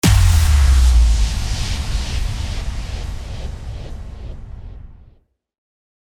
FX-1769-IMPACT
FX-1769-IMPACT.mp3